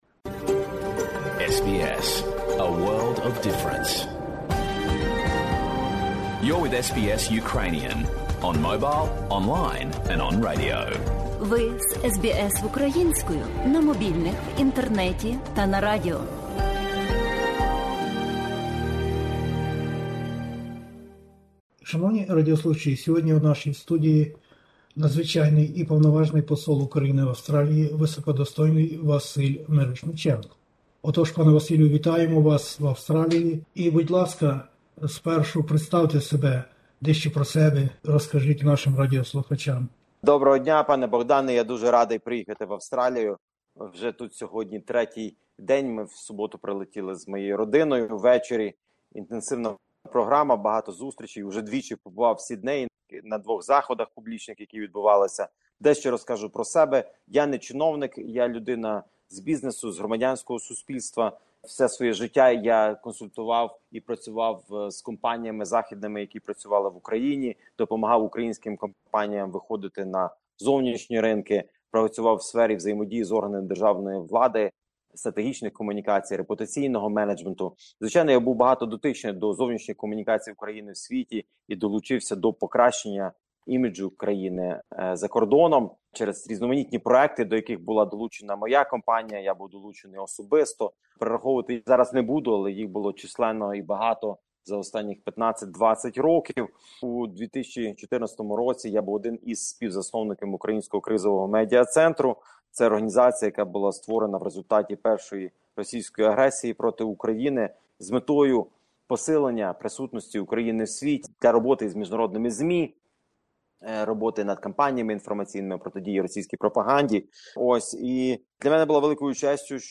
1 квітня 2022-го року у столиці Австралії відбулося вручення вірчих грамот кількома новими представниками країн і серед яких був новий Надзвичайний і Повноважний Посол України в Австралії Василь Мирошниченко. Розмову SBS Ukrainian iз високодостойним речником України пропонуємо вашій увазі...